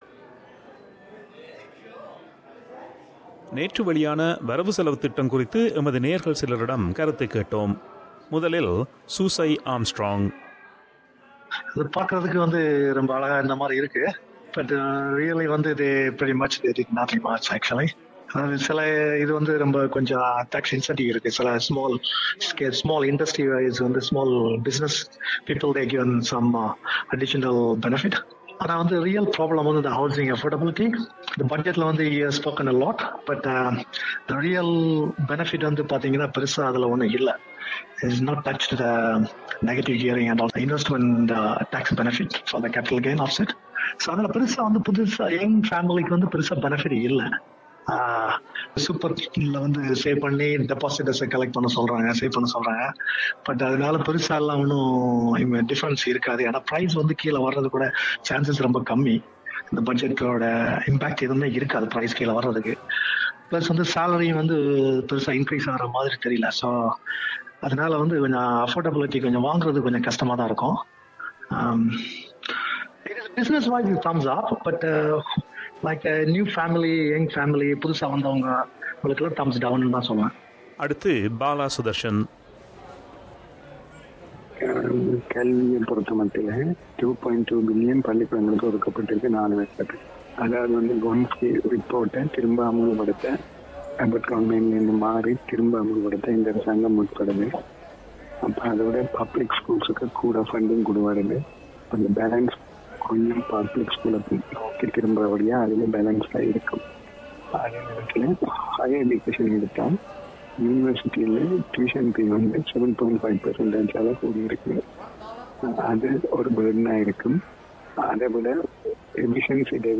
Voxpop on 2017 Budget